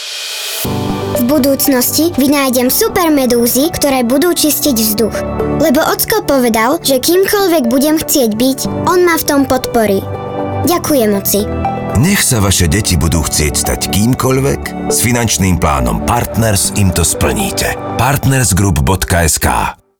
Rozhlasový spot dievča